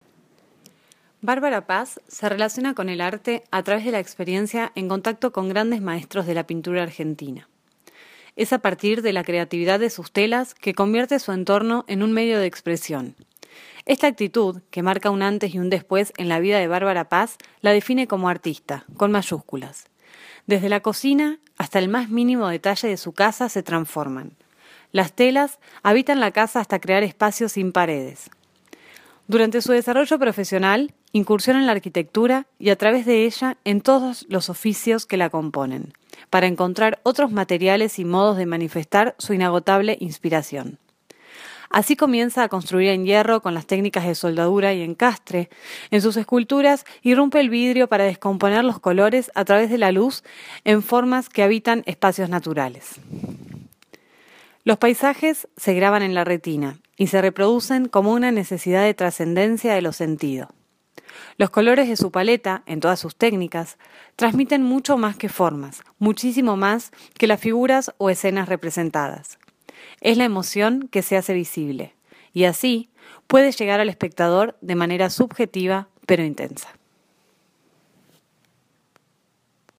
Un plus de la muestra son las audio guias, los visitantes podrán acceder por medio de su teléfono a un relato que ilustra conceptualmente el trabajo de cada artista, con solo sacar una foto a un codigo QR junto a las obras, (utilizando una rápida aplicación) se podrá conocer más sobre el universo simbólico de sus creadores.